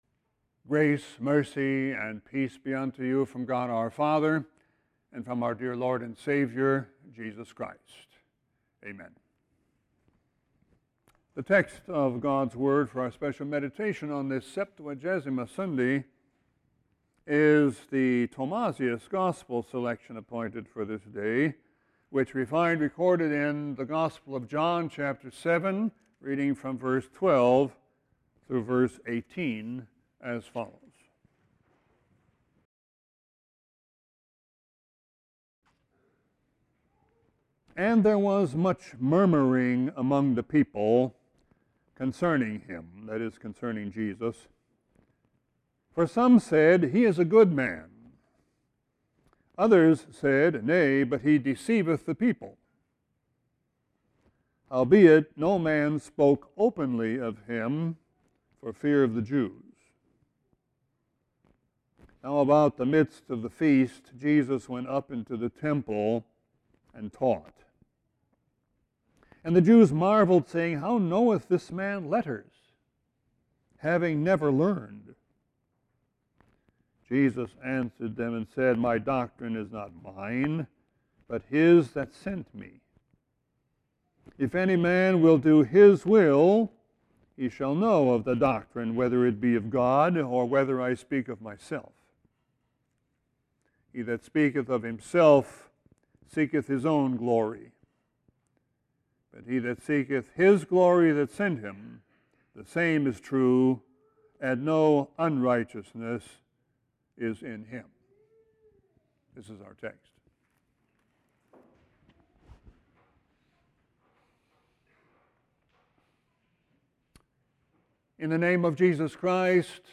Sermon 2-12-17.mp3